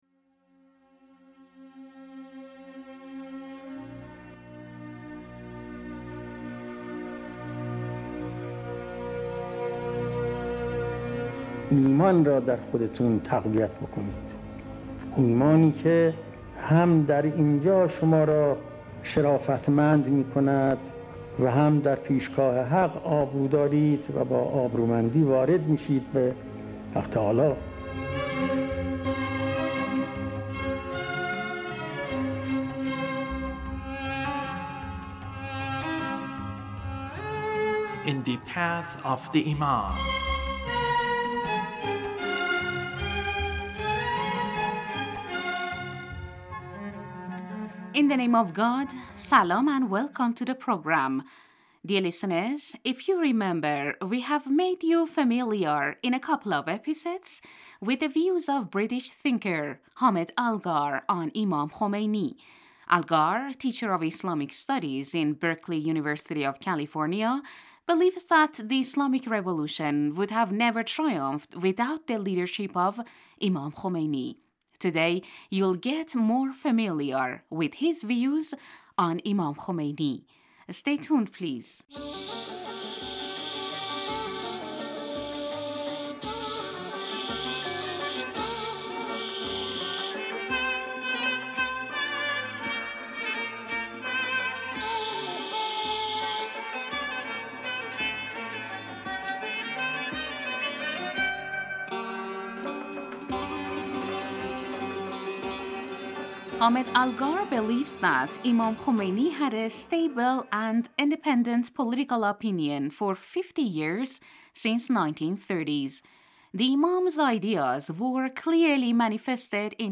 The radio series sheds light on the life of the Founder of the Islamic Republic of Iran, Imam Khomeini (God bless his soul) and his struggles against the S...